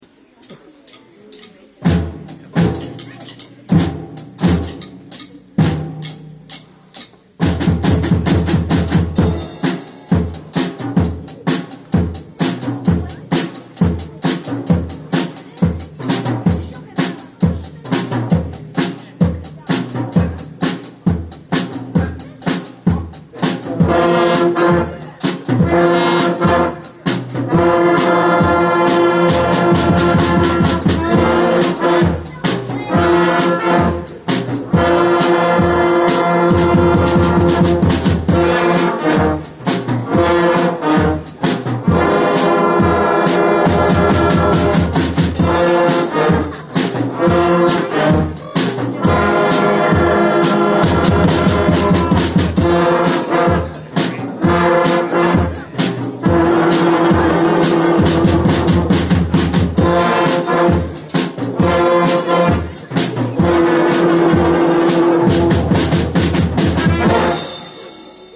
Ce lundi 16 décembre 2019, les “Graines de musiciens” ont livré leur première prestation devant leurs camarades de l’école.
Les élèves (plutôt stressés pour cette première représentation en public) ont brillamment relevé le défi en interprétant 3 morceaux successifs.